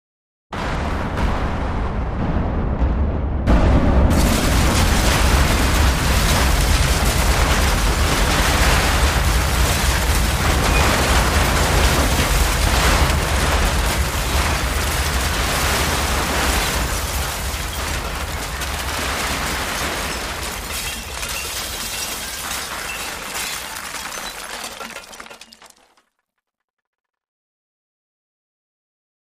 Building Demolition